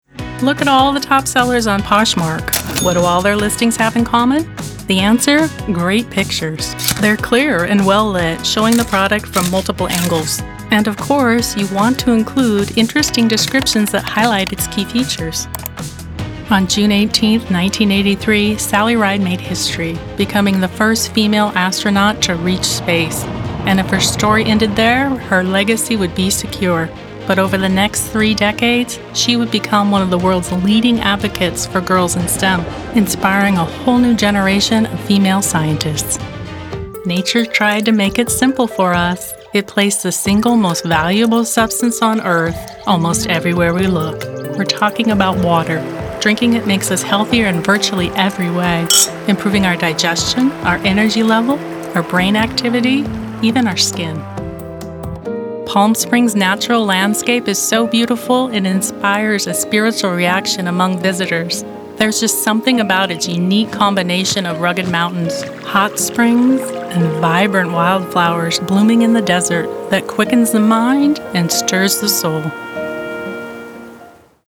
English - USA and Canada
Young Adult
Middle Aged